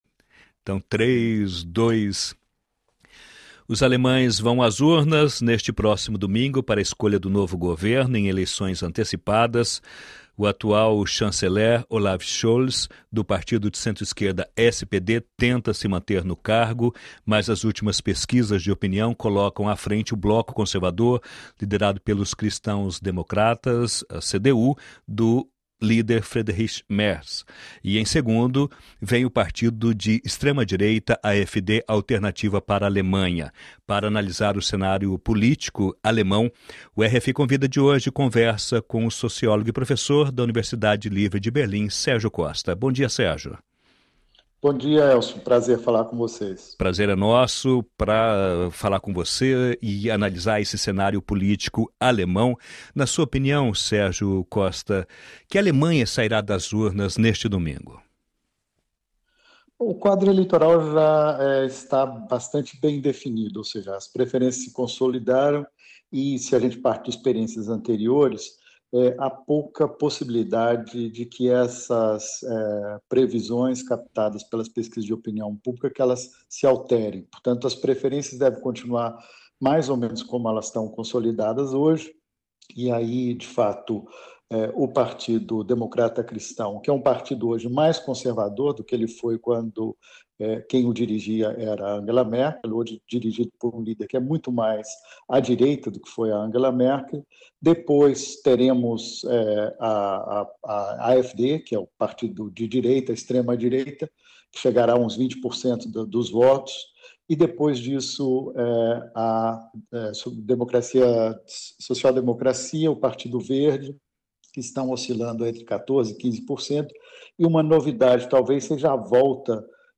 Entrevistas diárias com pessoas de todas as áreas. Artistas, cientistas, professores, economistas, analistas ou personalidades políticas que vivem na França ou estão de passagem por aqui, são convidadas para falar sobre seus projetos e realizações.